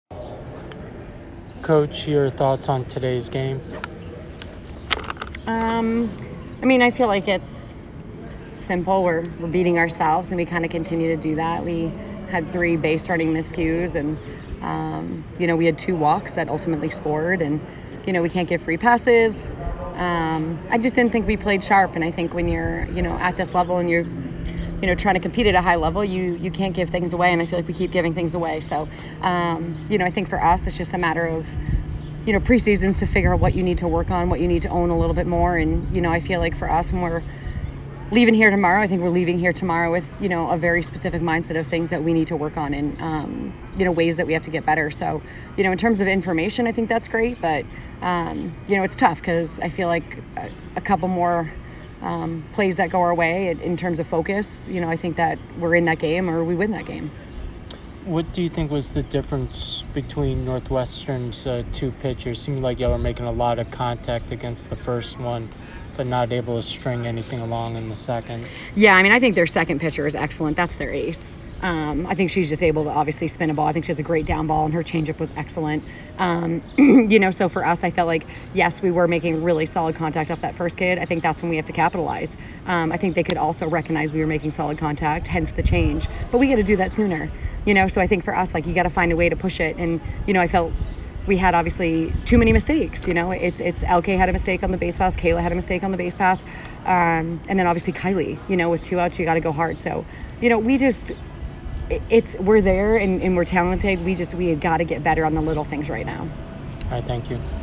Northwestern Postgame Interview